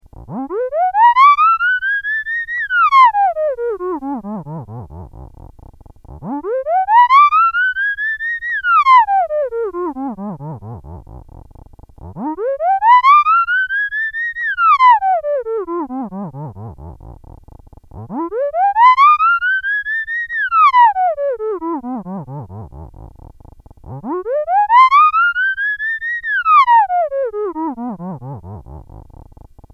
This article gives seven examples of sounds you can produce with Sound Lab, the simple single-board analogue synthesiser that I described in the earlier article Sound Lab - a Simple Analogue Synthesiser:
Hysterical laughter
hysterical.mp3